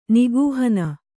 ♪ nigūhana